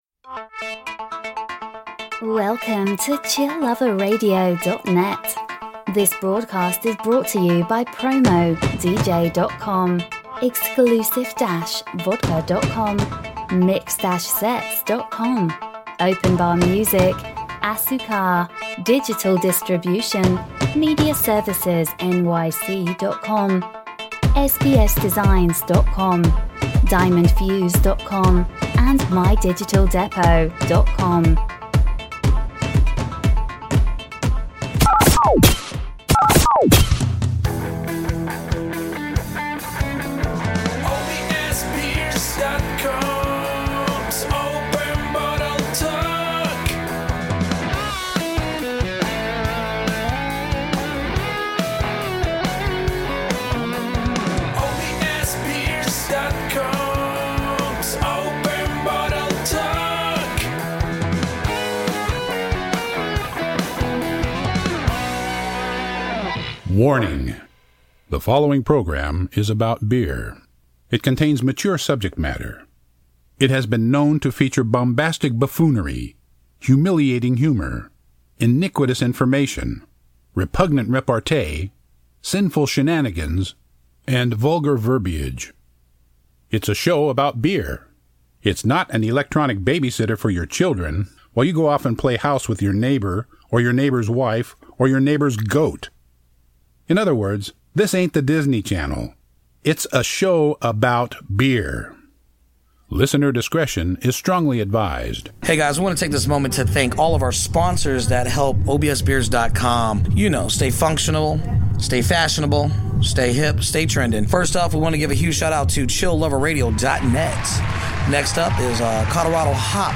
Styles: Beer Talk, Beer News, Beer, Craft Beers, Talk Show, Comedy